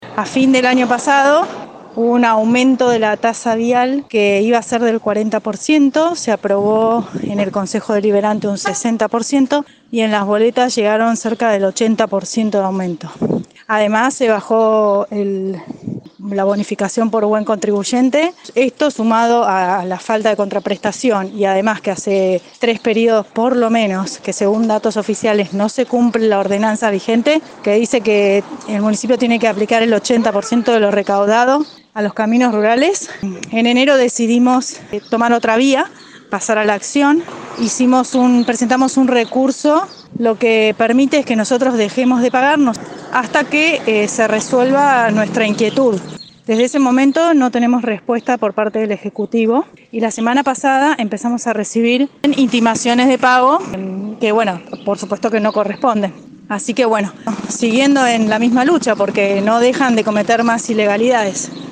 En diálogo con EL CAMPO HOY